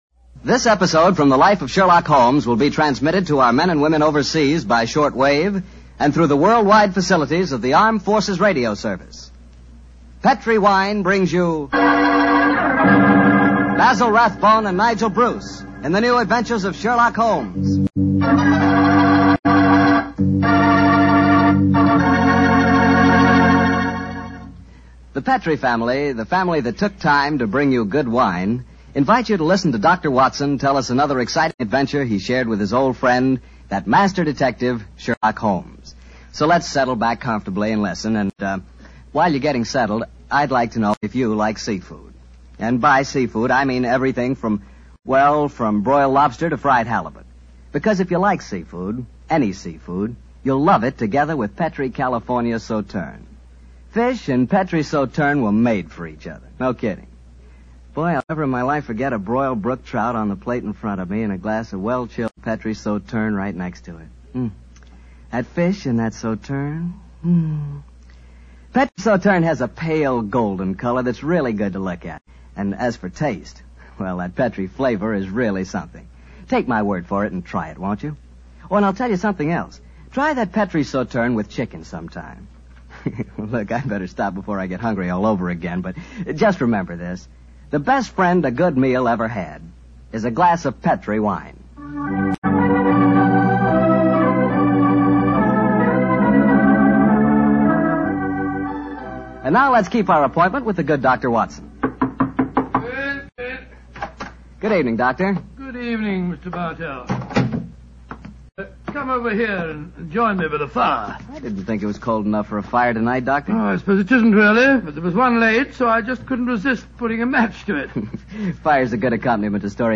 Radio Show Drama with Sherlock Holmes - Murder By Moonlight 1945